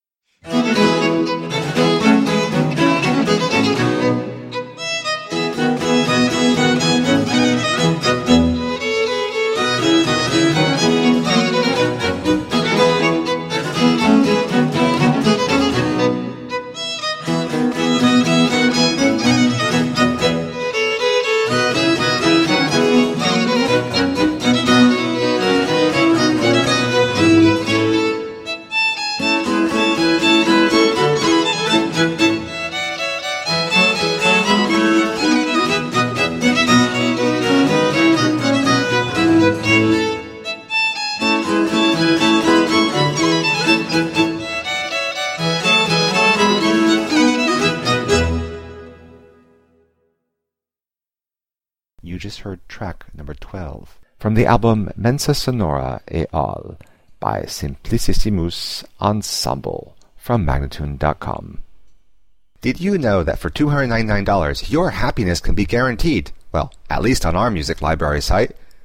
17th and 18th century classical music on period instruments.